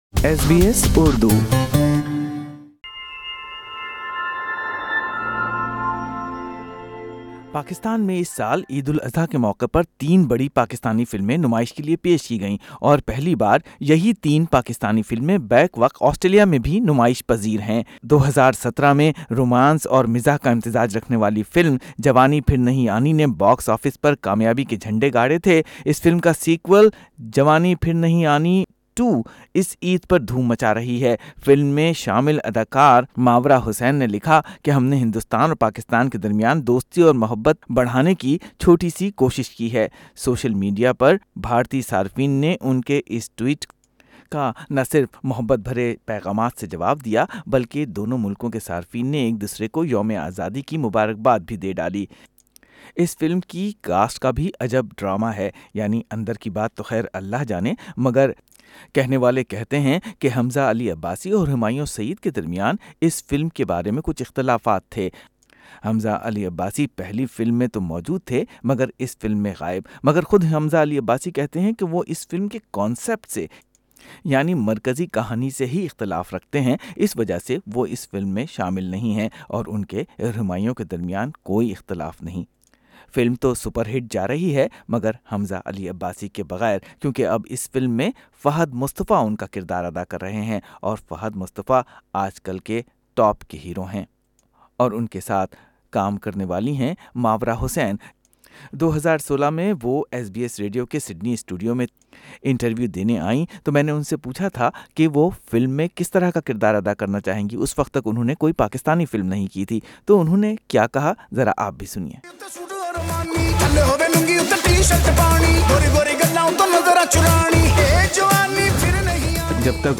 سنئیے فلمی دنیا کی دلچسپ باتوں کے ساتھ ماروا حسین اور احد رضا میر کی باتیں فلموں کی صوتی جھلکیوں کے ساتھ۔۔